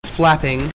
flapping3
Category: Animals/Nature   Right: Personal